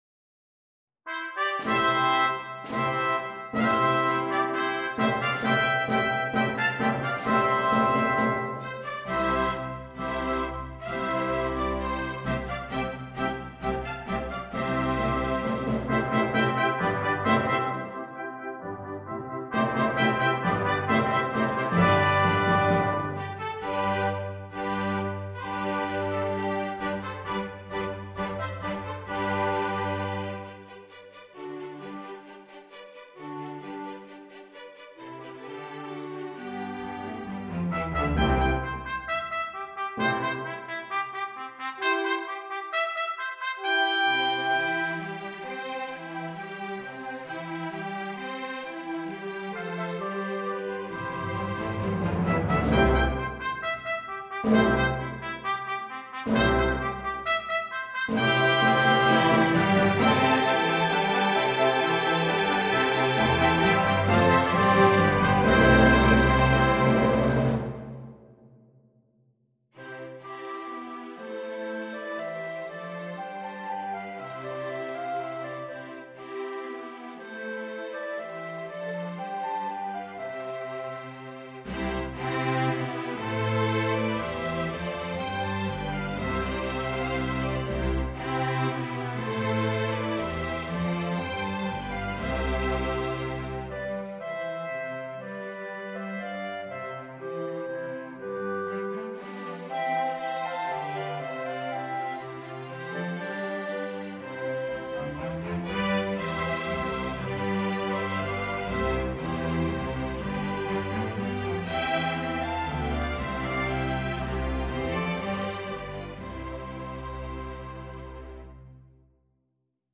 Orchestration
2 Flutes, 2 Oboes, 2 Clarinets in Bb, 2 Bassoons
Strings (Violin 1, Violin 2, Viola or Violin 3, Cello, Bass)
arranged for orchestra